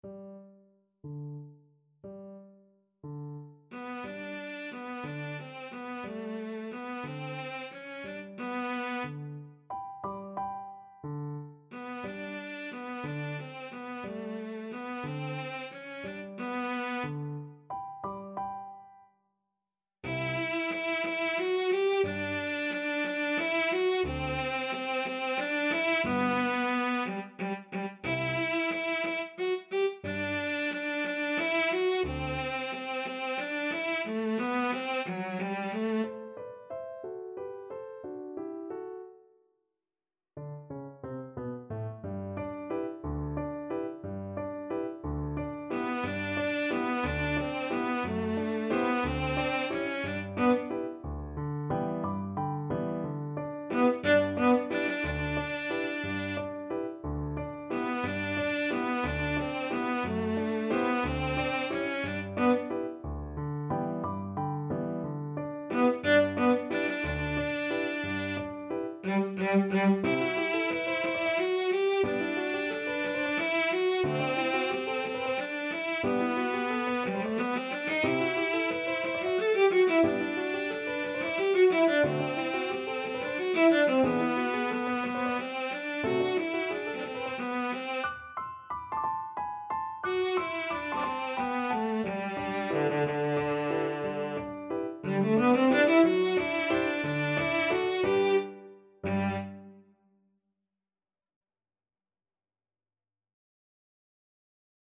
6/8 (View more 6/8 Music)
Pochissimo pi mosso = 144 . =60
D4-A5
Classical (View more Classical Cello Music)